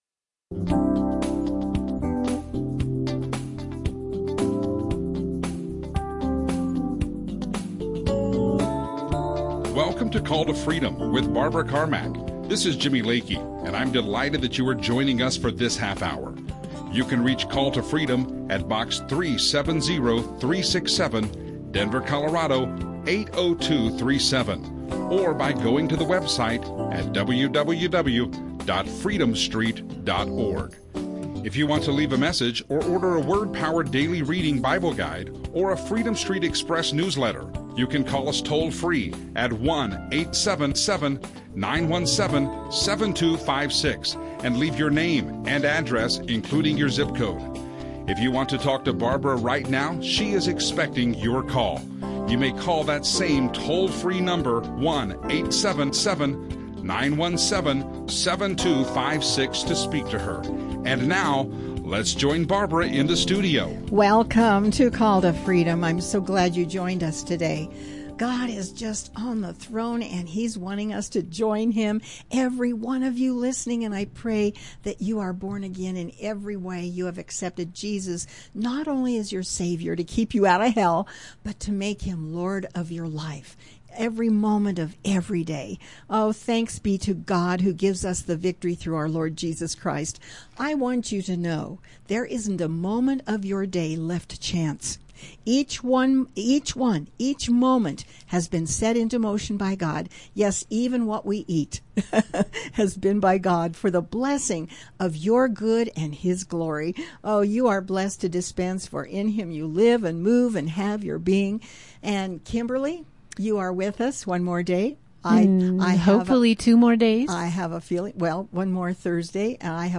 Call to Freedom Christian radio
radio show